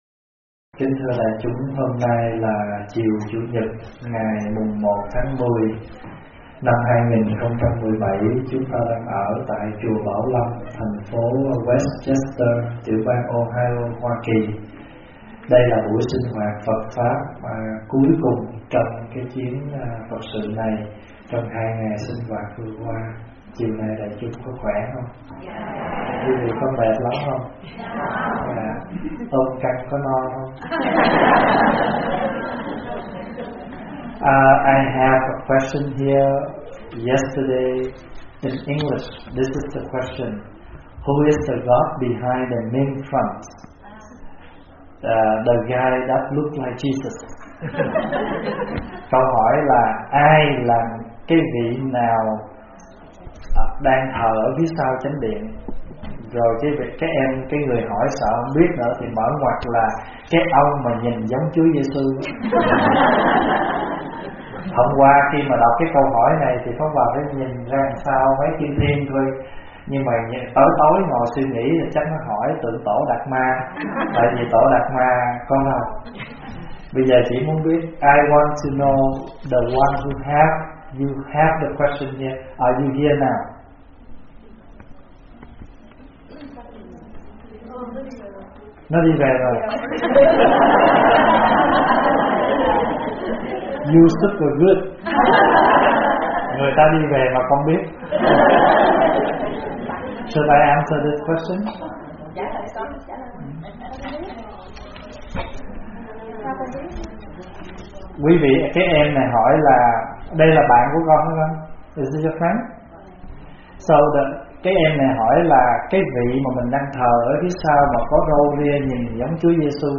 Nghe Mp3 thuyết pháp Soi lại tâm mình qua sự tĩnh lặng